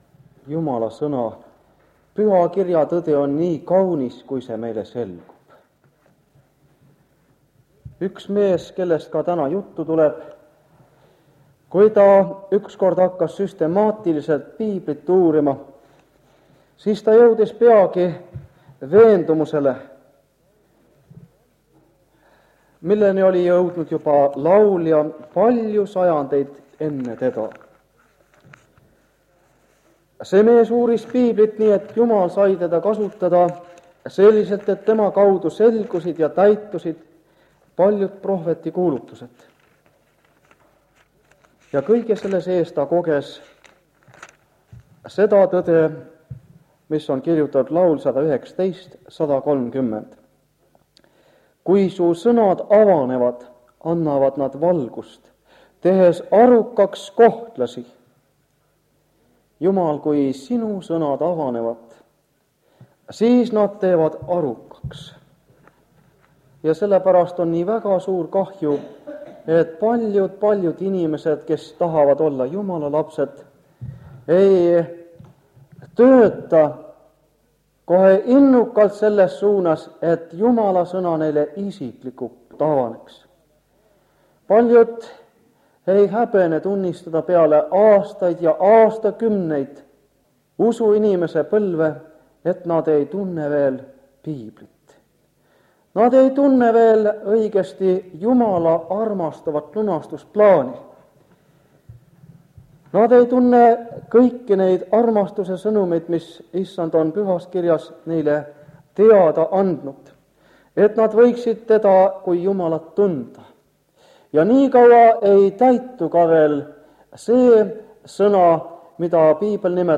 Jutlused
Ilmutuse raamatu seeriakoosolekud Kingissepa linna adventkoguduses